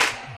Clap (Power).wav